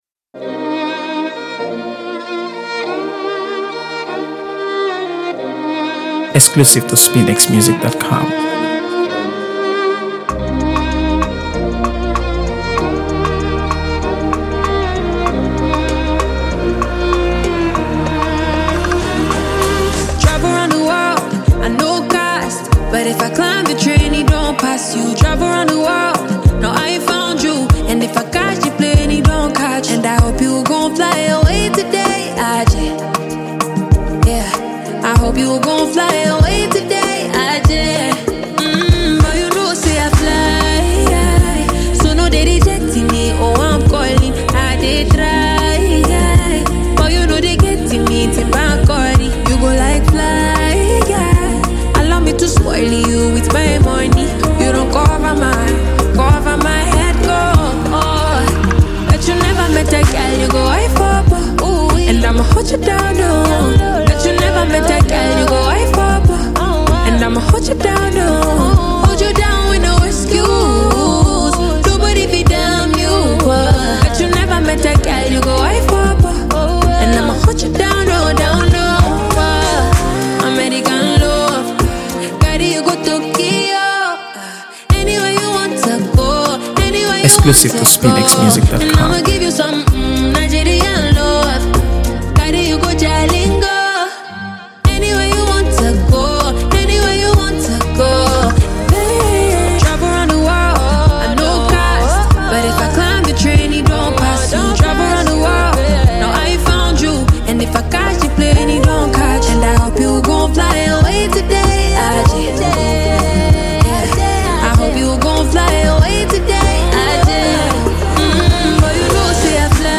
AfroBeats | AfroBeats songs
Afrobeat and Afropop
Known for her unique sound and soulful delivery